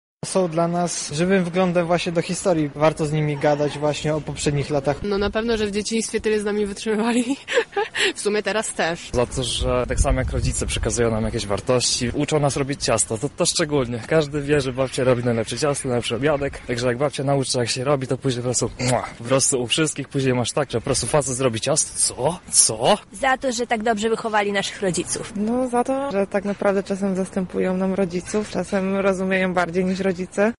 Za co powinniśmy być wdzięczni naszym babciom – o to zapytaliśmy mieszkańców Lublina:
Sonda